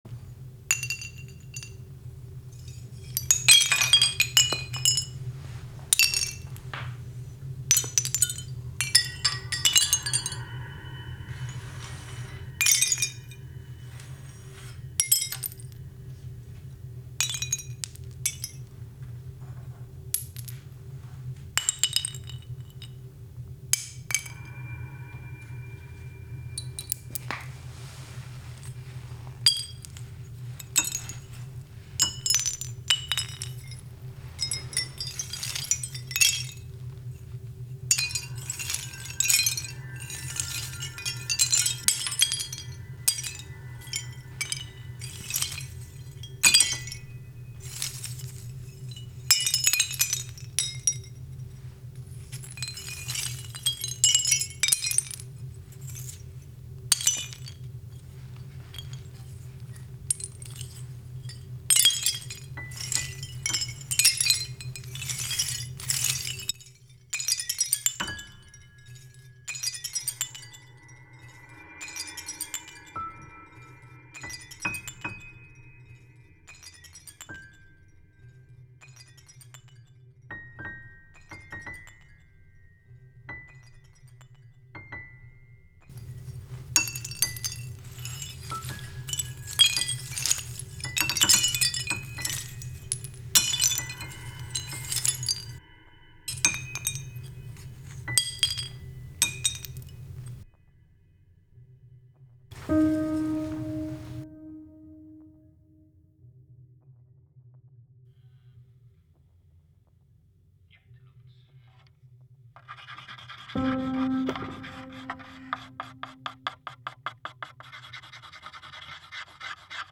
SOUNDSCAPE : HOUTSKOOL
De geluiden heb ik gevormd tot een compositie waarin de hoge tonen van het vallende houtskool worden gekopieerd door de piano. Zo ontstaat een melodieuze, abstracte tonale verklanking van het houtskool. Uiteindelijk zijn er ook stemmen te horen die als het ware het innerlijk van het houtskool naar boven brengen…